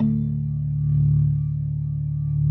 B3LESLIE D 2.wav